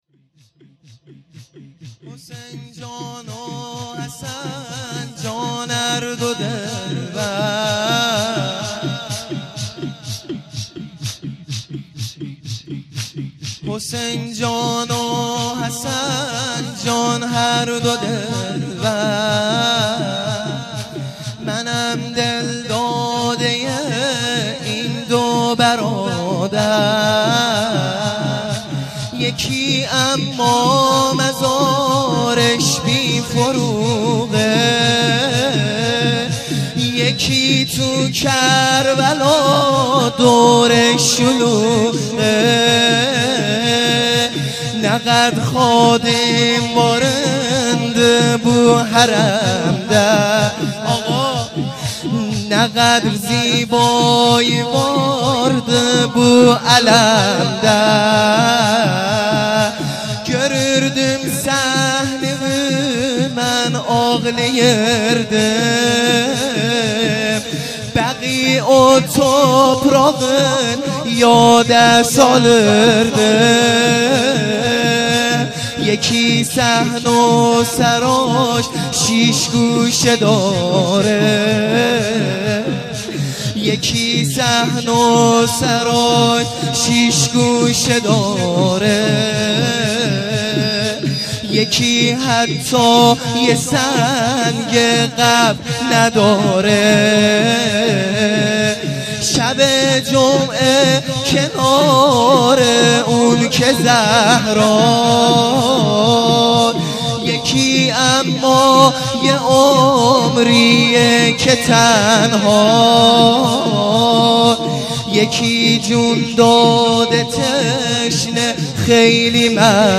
شور|حسین جان حسن جان